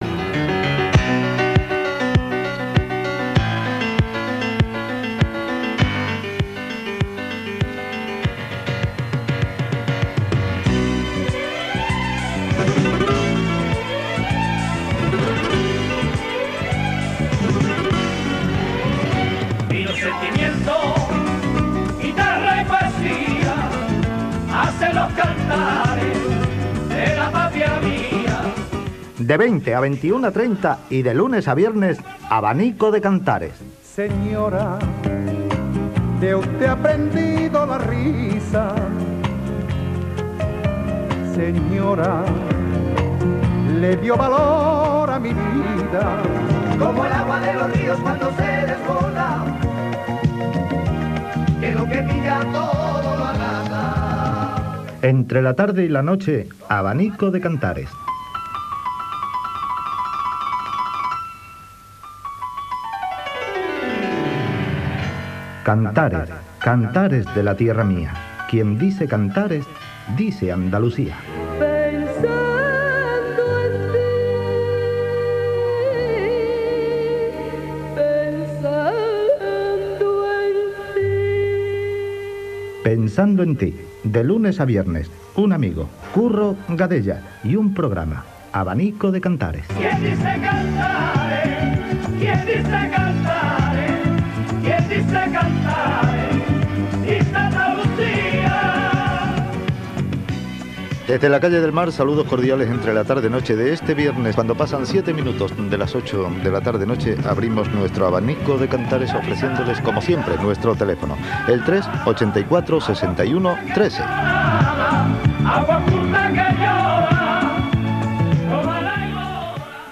Careta del programa, presentació, telèfon
Musical